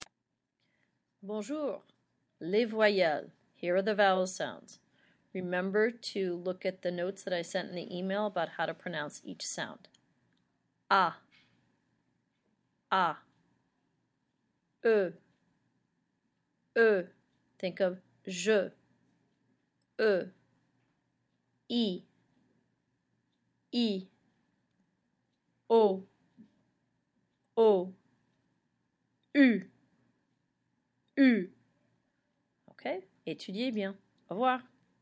French vowel sounds